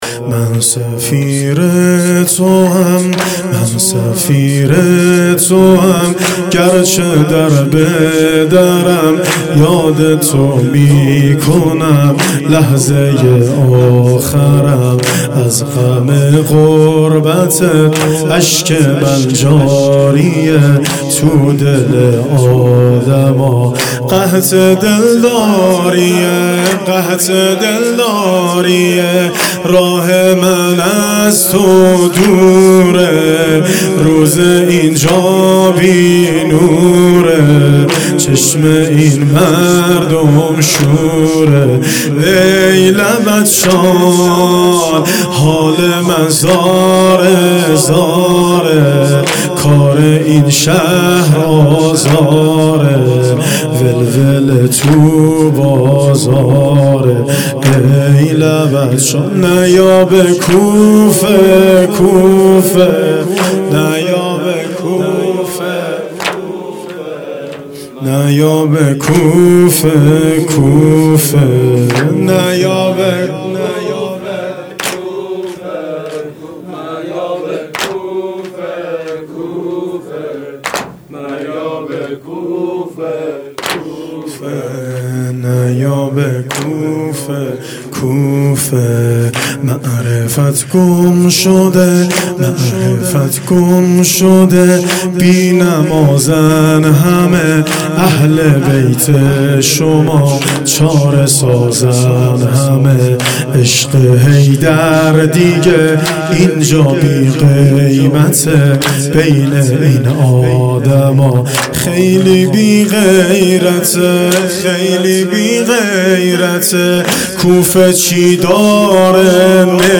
شب اول محرم 1400